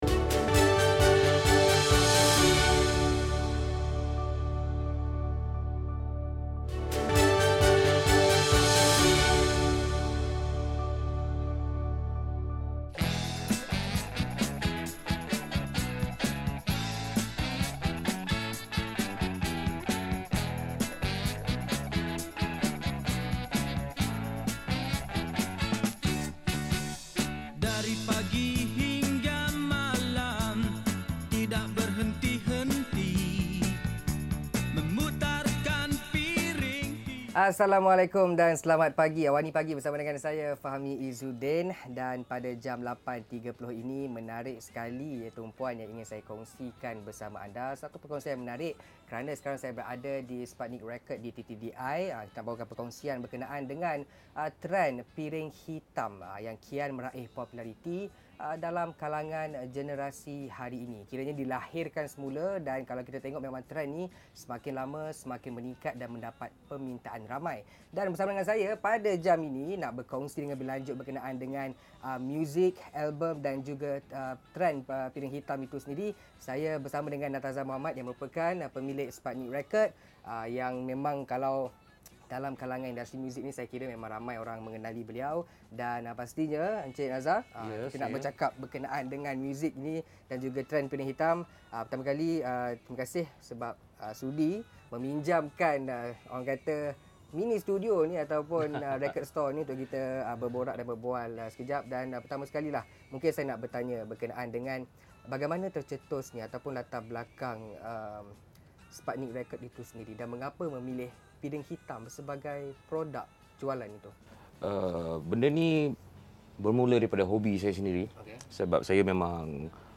AWANI Pagi, 8.30 pagi Sabtu mengetengahkan simfoni piring hitam dan menyelami kekayaan emosi seni muzik bersama penyampai